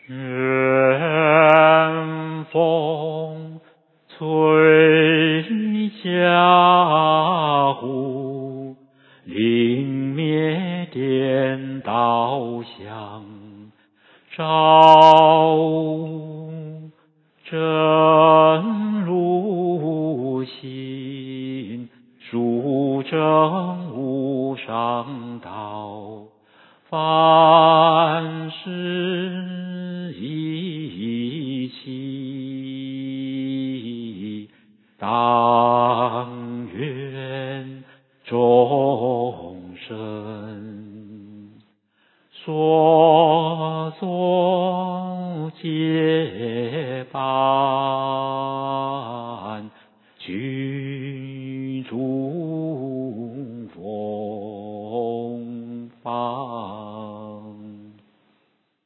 梵呗教学音档